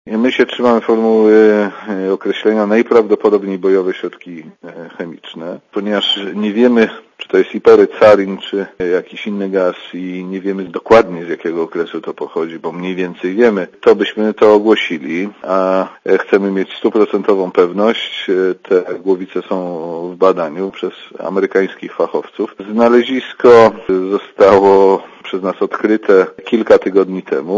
Dla Radia ZET mówi minister Szmajdziński